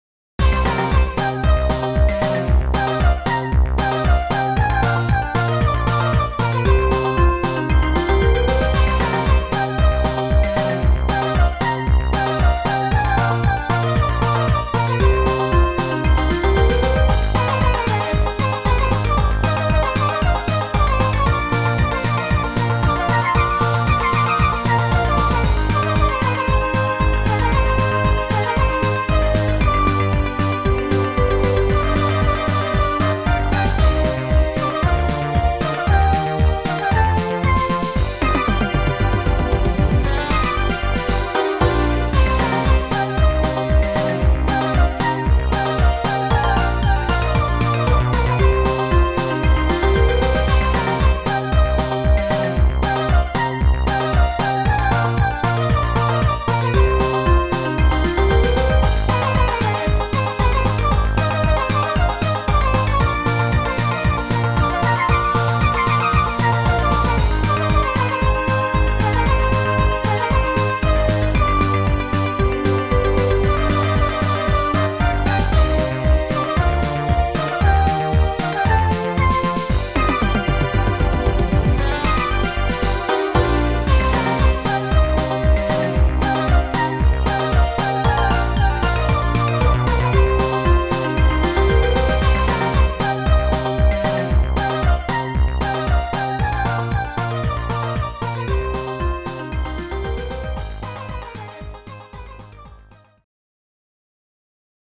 怪しさ溢れる和音の名曲。